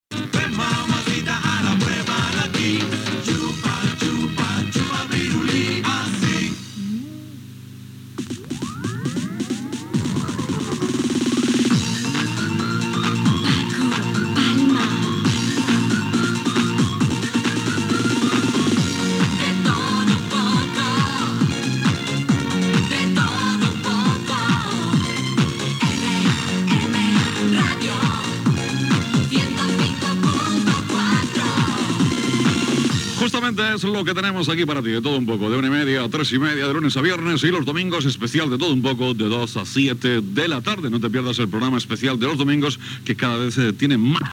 d54540c2eda10999e2f1f6fc06adba05c023f064.mp3 Títol RM Radio Emissora RM Radio Titularitat Privada local Nom programa De todo un poco Descripció Indicatiu del programa, dies i hores d'emissió.